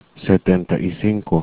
The 60's & 70's sound very much alike, so careful work is needed on these to make certain the differentiation is clear.
1st syllable: se (as in set)
2nd syllable: ten (also means "here, take this")
3rd syllable: ta